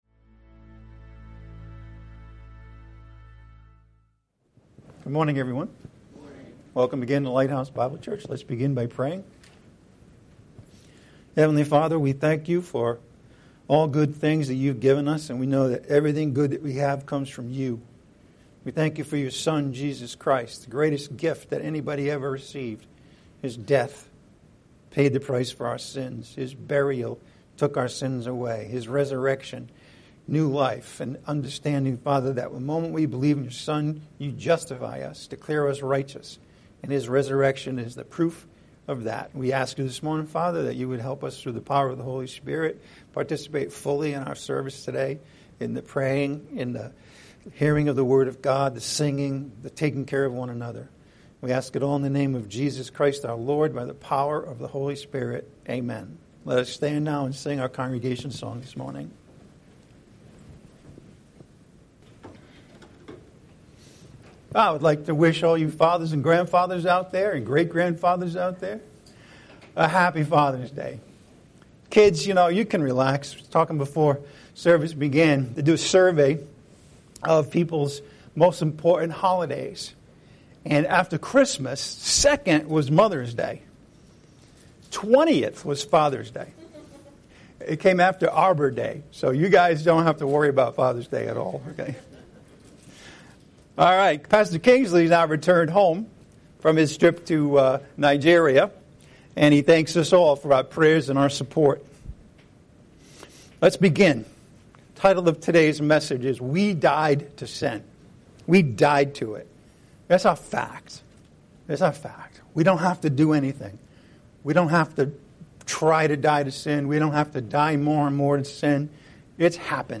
Lighthouse Bible Church (LBC) is a no-nonsense, non-denominational, grace oriented and Bible centered Christian church.